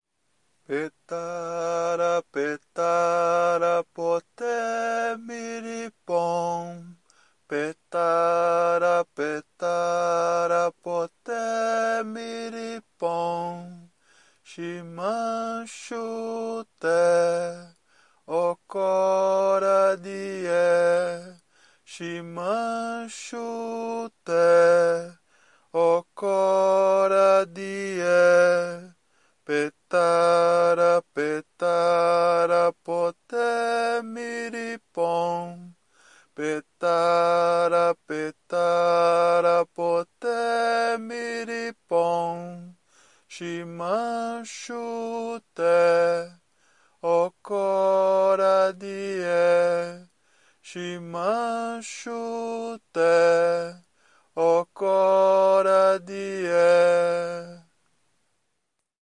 Una canzone tradizionale brasiliana, “Petara”, in versione cantata e in versione suonata al flauto di bambù